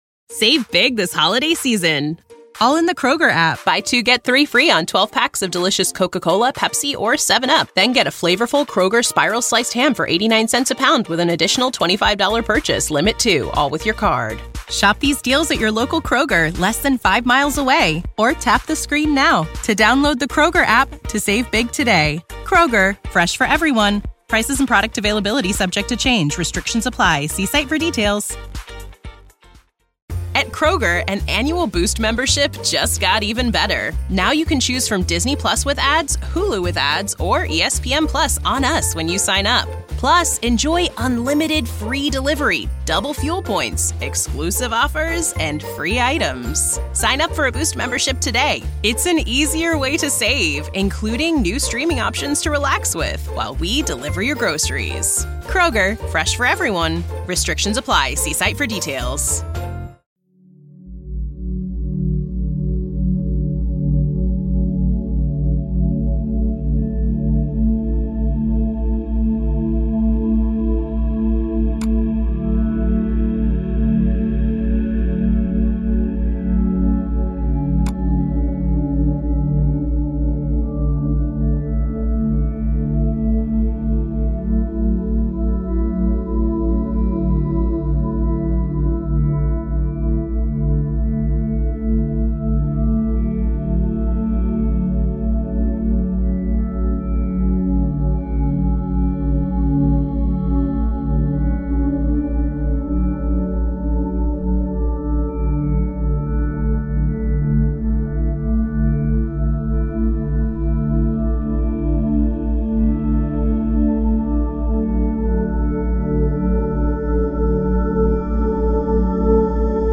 Hypnosis and relaxation ｜Sound therapy
Here is a wonderful dreamland to help sleep and relax.